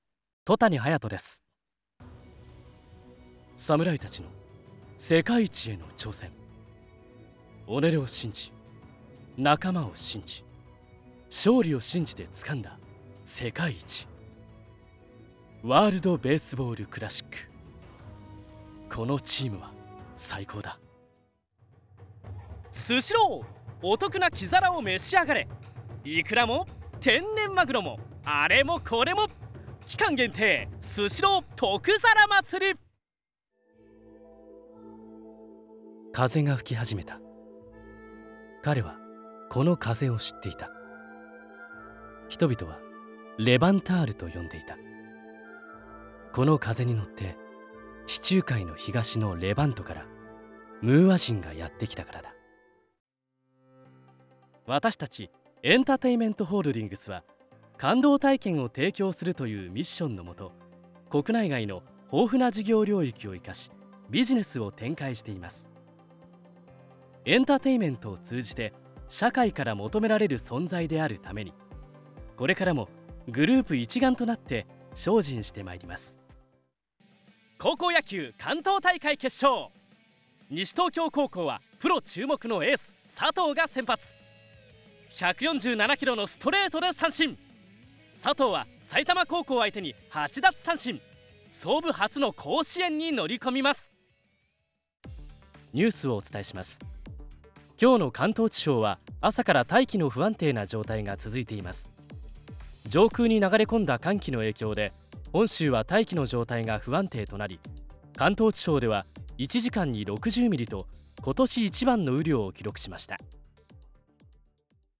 デビューしたレッスン生の、簡単な芸歴とボイスサンプルです。
ボイスサンプル